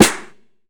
CDK Wavy Snare.wav